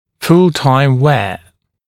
[ful-taɪm weə][фул-тайм уэа]постоянное ношение